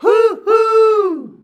HUHUUUH A.wav